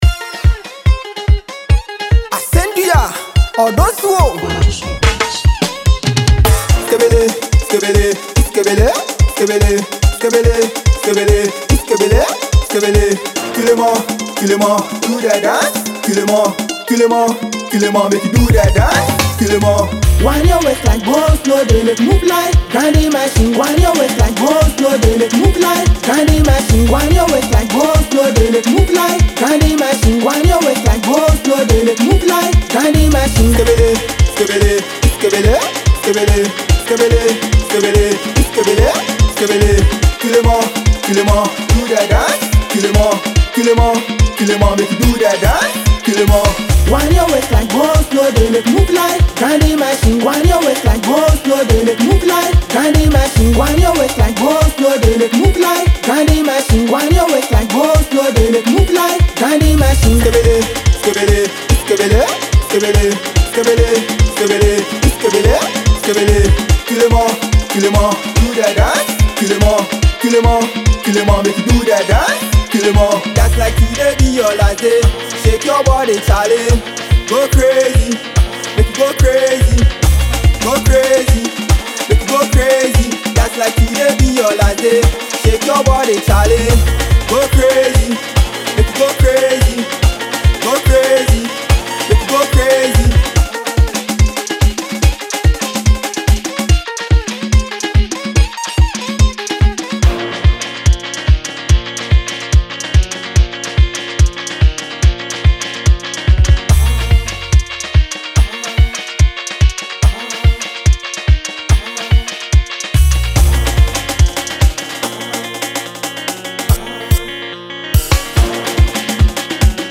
energetic and infectious track
With a fusion of upbeat rhythms and vibrant melodies